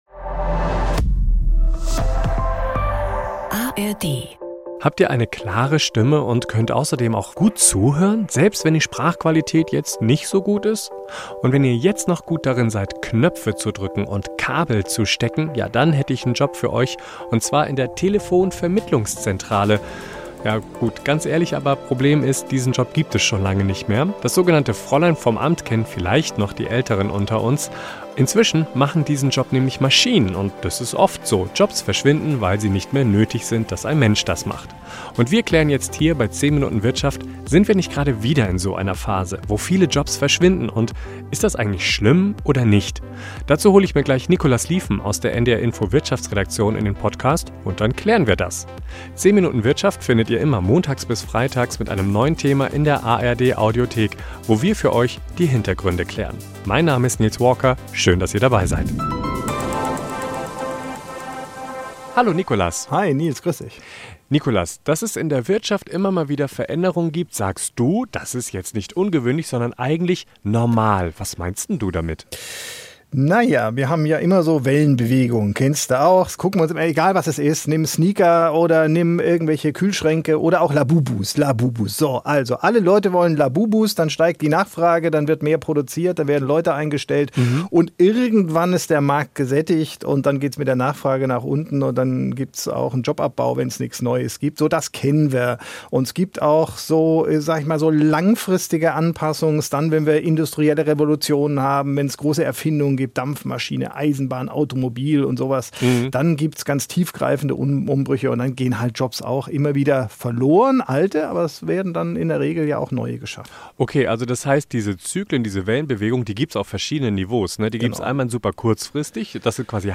beide übrigens echte Menschen, versprochen!